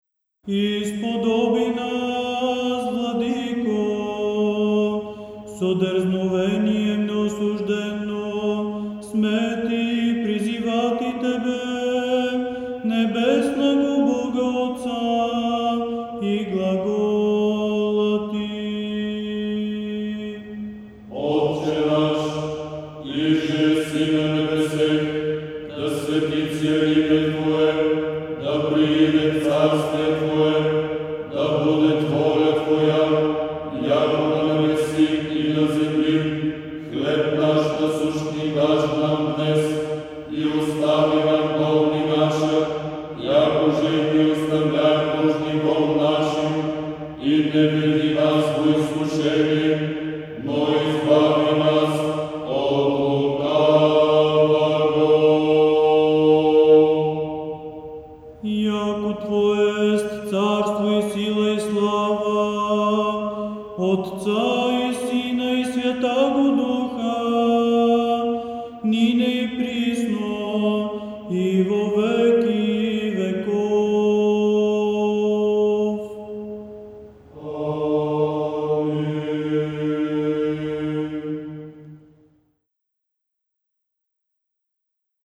Речитатив